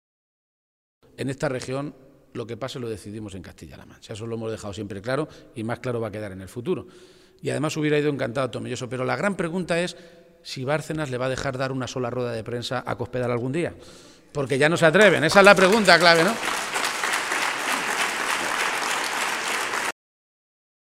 Momento de la intervención de García-Page
El secretario general de los socialistas de Castilla-La Mancha, Emiliano García-Page, ha mantenido este mediodía un encuentro con militantes y simpatizantes del PSOE de la comarca de La Sagra toledana en la localidad de Illescas, y ha aprovechado su intervención para hacer referencia a algunos de los asuntos que están marcando la actualidad en las últimas semanas.